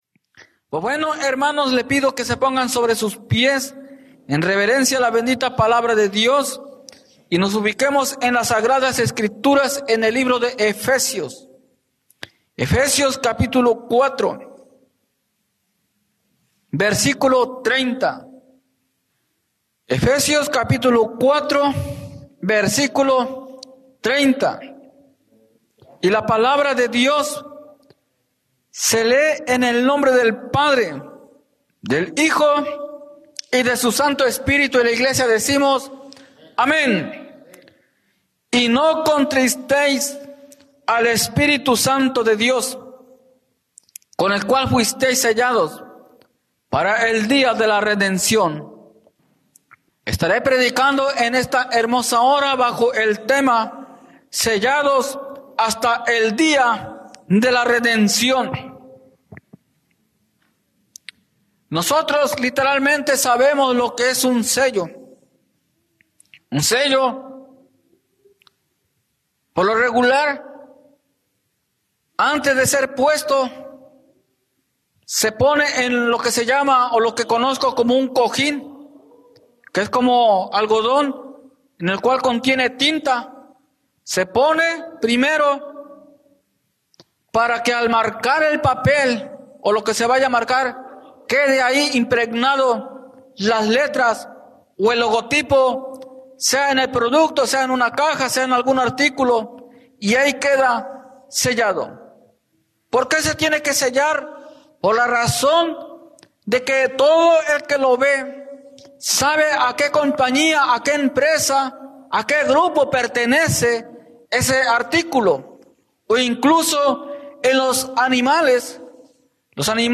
Sellados hasta el día de la redención Predica